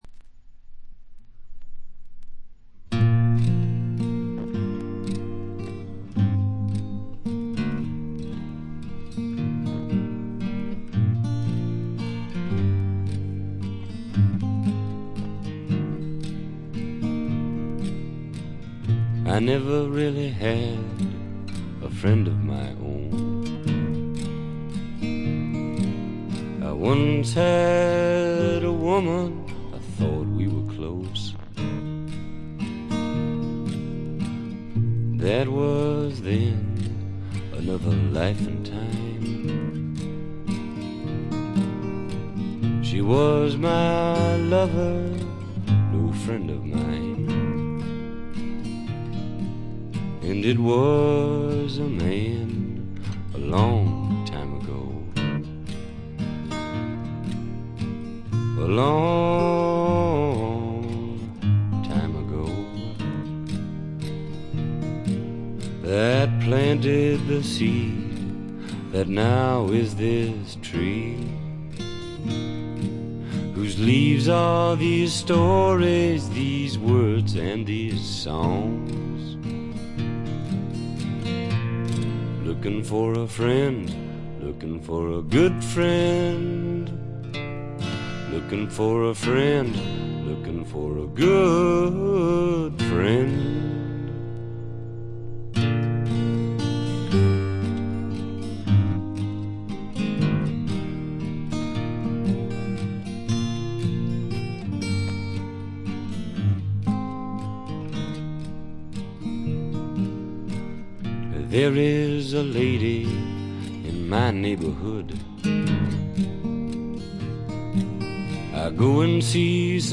試聴曲は現品からの取り込み音源です。
Slide Guitar